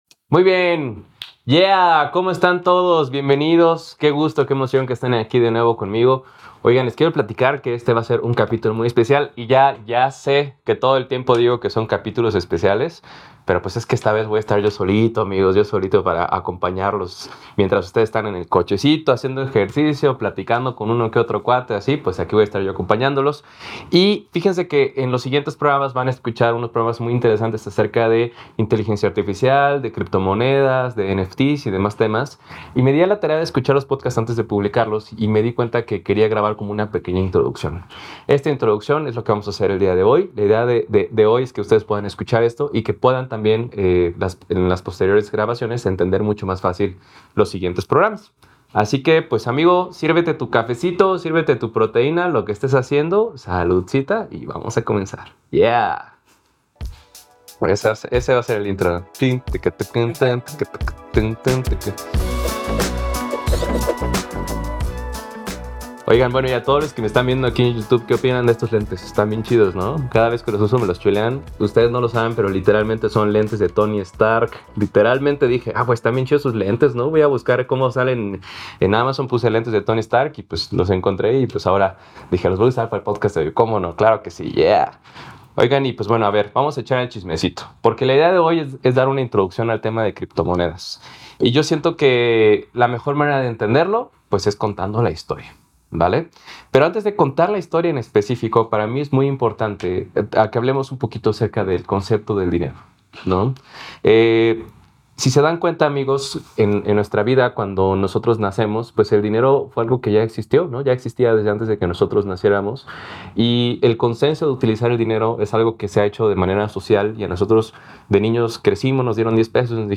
Exploraremos las oportunidades y desafíos que ha encontrado en este mundo financiero en constante evolución, y cómo hoy comparte su conocimiento a través de conferencias sobre blockchain y la tokenización de activos. No te pierdas esta charla llena de lecciones y consejos sobre el futuro de las finanzas y la innovación en el siglo XXI.